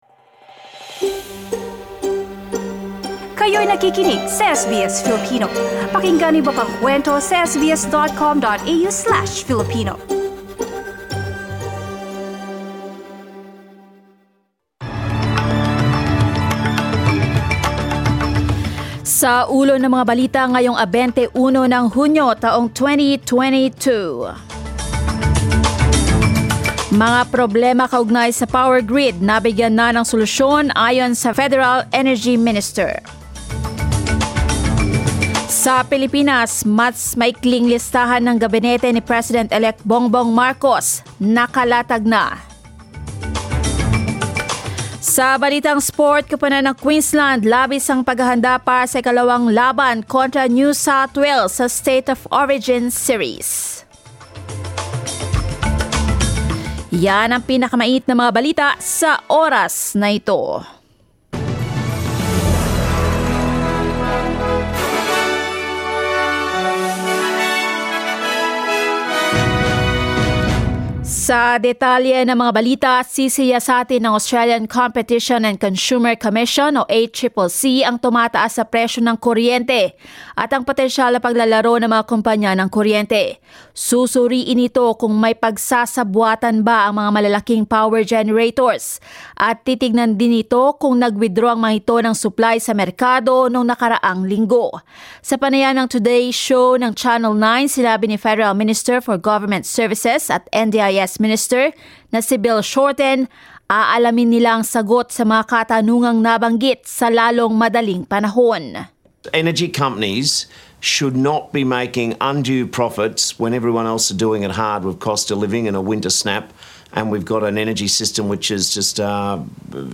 SBS News in Filipino, Tuesday 21 June